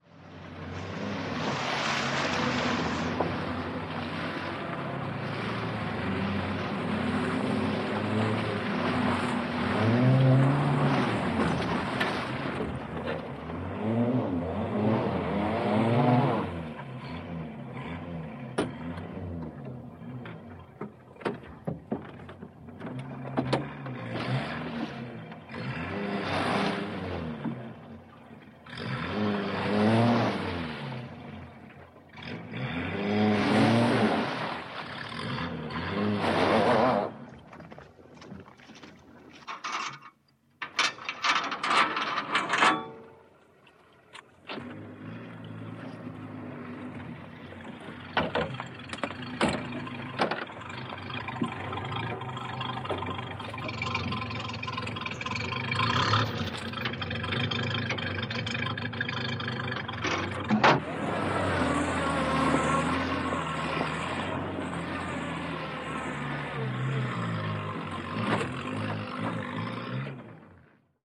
На этой странице собраны реалистичные звуки пробуксовки автомобиля в разных условиях: на льду, в грязи, на мокром асфальте.
Грузовик застрял в грязи, газует и буксует на месте